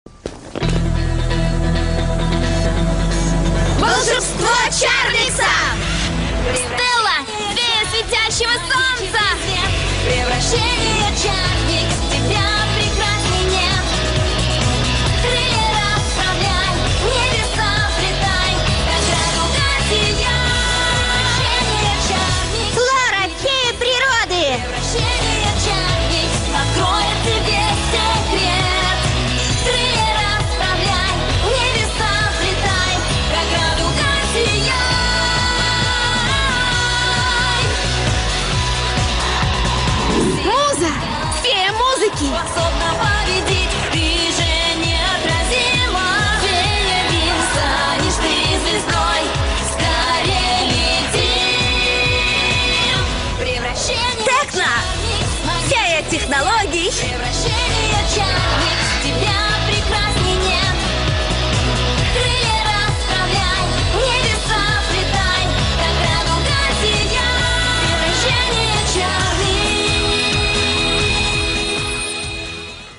• Качество: Хорошее
• Жанр: Детские песни
🎶 Детские песни / Песни из мультфильмов